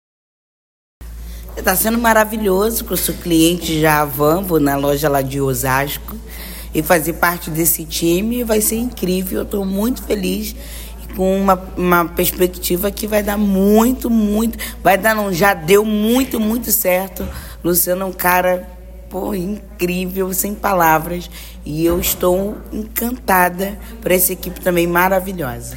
Ouça o que Jojo Todynho falou após confirmar ser o novo “rosto” da Havan